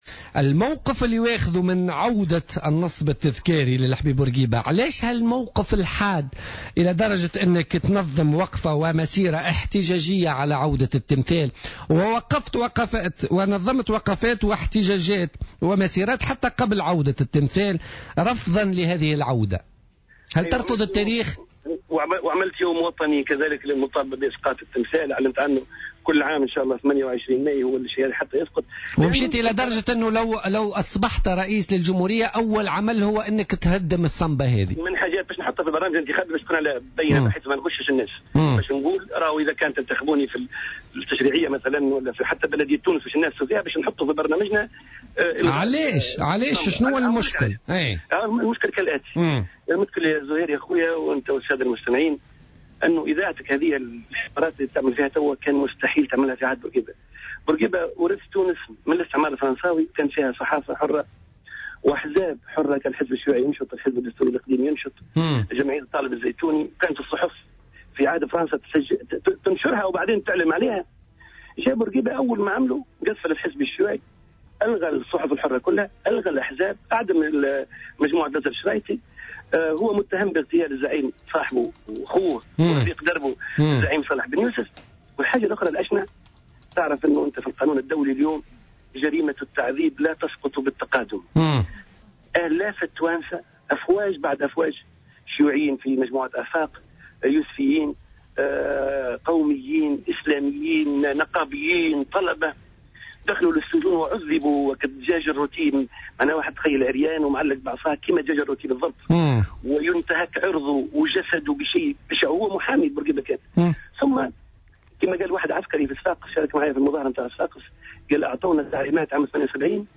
قال رئيس تيار المحبة الهاشمي الحامدي في اتصال هاتفي مع برنامج "بوليتيكا" بـ "الجوهرة اف أم" اليوم الاثنين إن هناك عدة أسباب دفعته الى الاحتجاج على ارجاع تمثال الزعيم الحبيب بورقيبة بالشارع الذي يحمل اسمه.